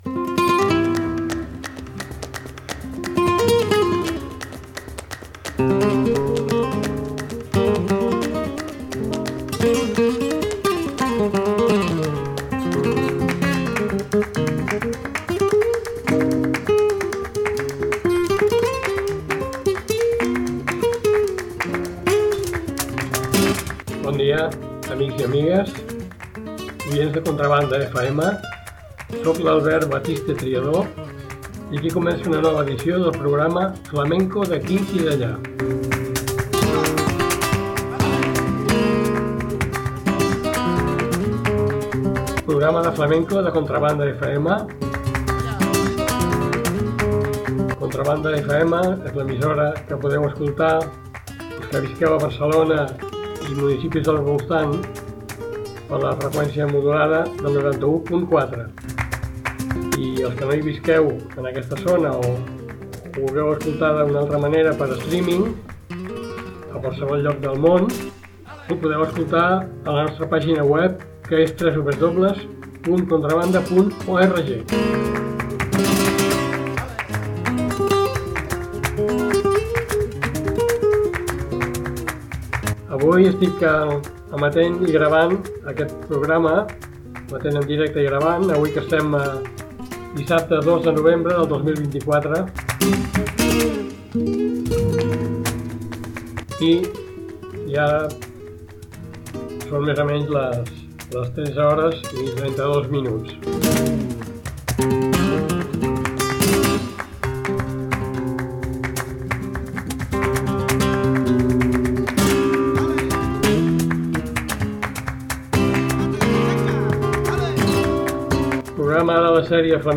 Marina Heredia, cantaora.
Tangos de Granada, Bulerias, Romance de la dulce queja i Alegrías. https